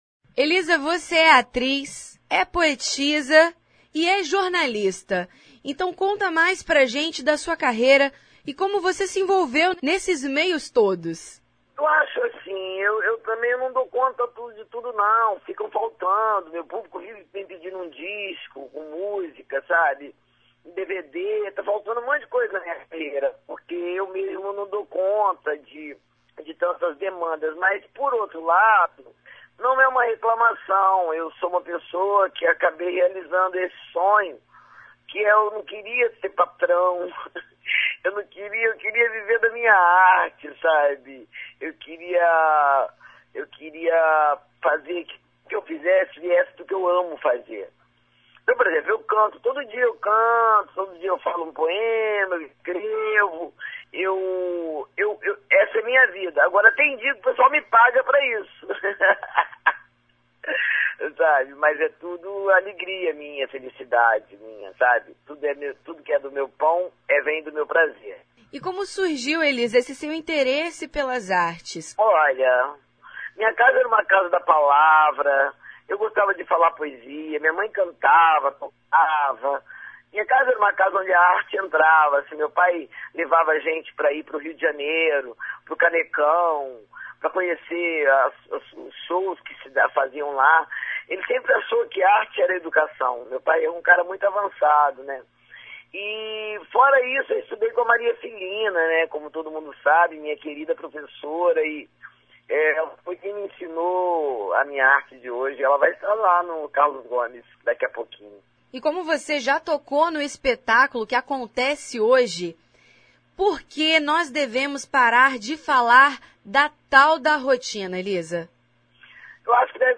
Confira a entrevista que o Revista Universitária fez com a atriz e poeta, Elisa Lucinda.
1696-_entrevista_elisa_lucinda.mp3